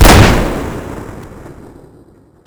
shotgun_fire6.wav